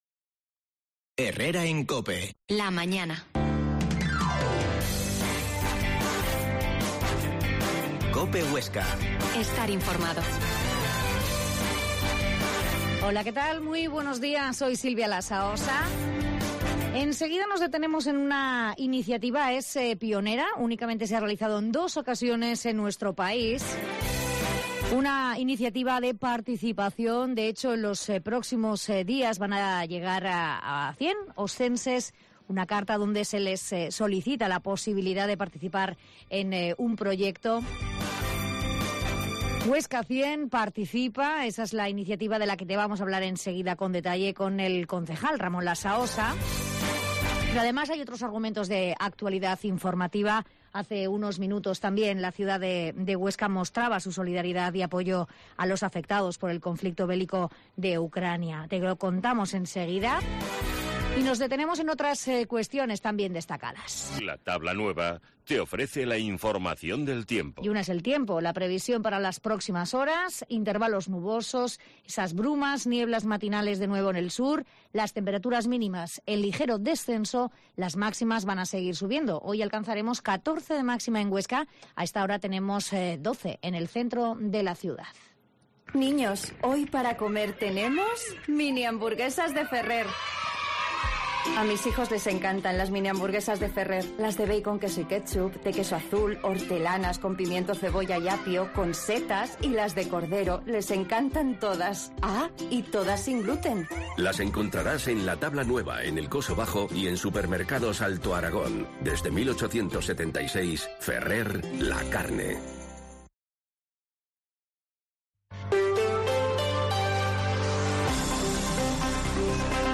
Herrera en COPE Huesca 12.50h Entrevista al concejal de participación, Ramón Lasaosa sobre "Huesca 100"